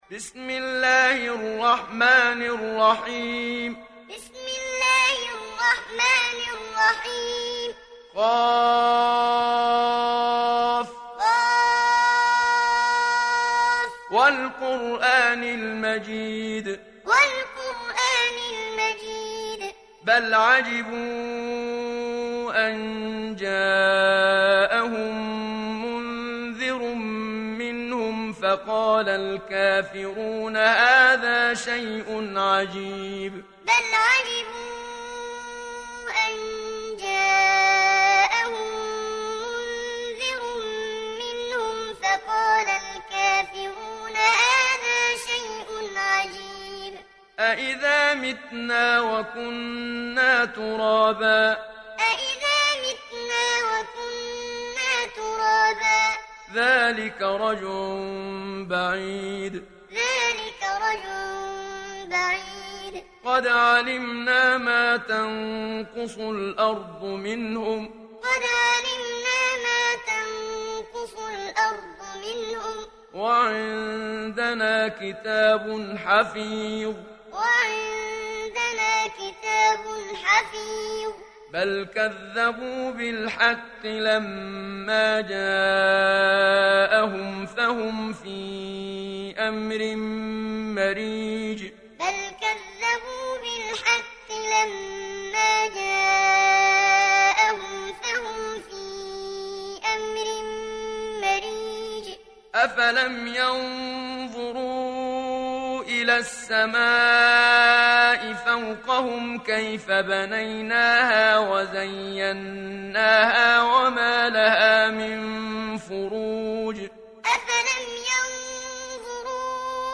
دانلود سوره ق محمد صديق المنشاوي معلم